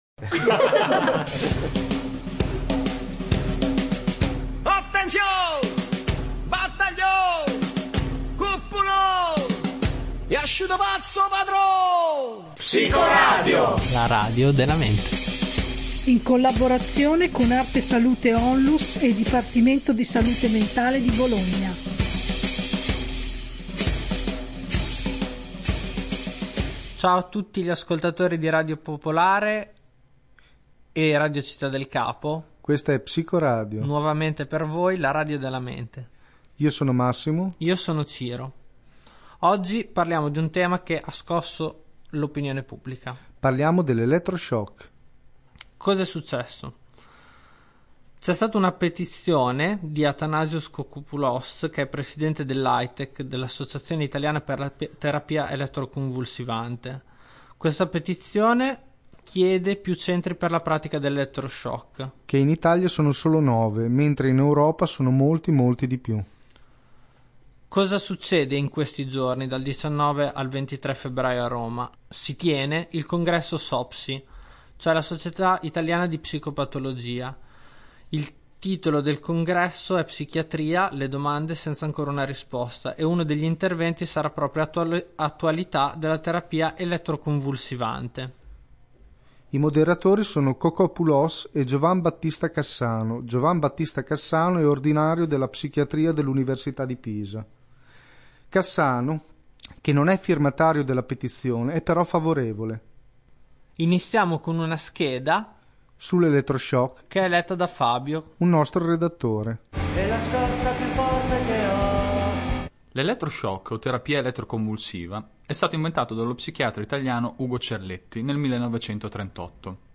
una trasmissione informativa su questa terapia